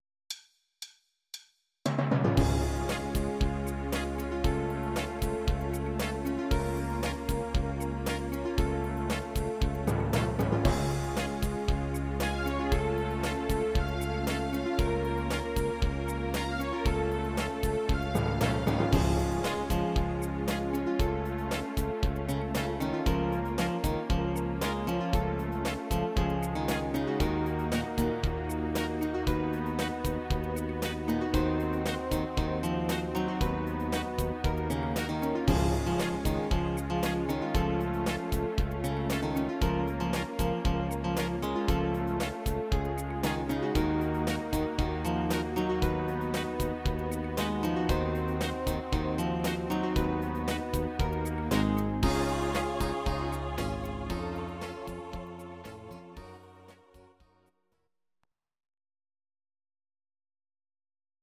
These are MP3 versions of our MIDI file catalogue.
Please note: no vocals and no karaoke included.